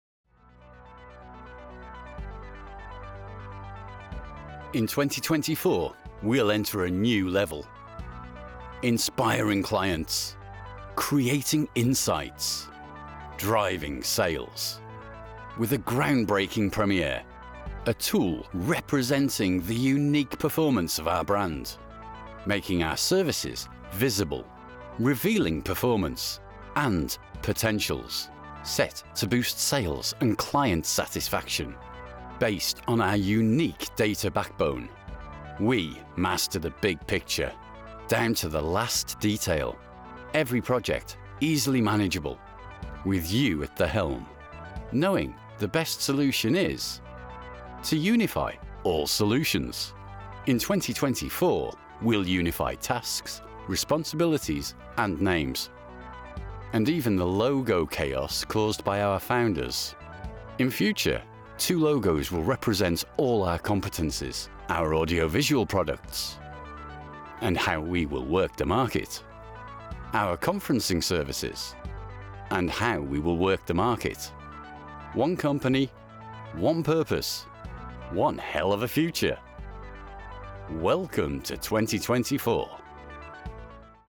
A seasoned, full, deep, mellifluous Britsh English gentleman.
Corporate Pitch
World English, British, UK, Mid-Atlantic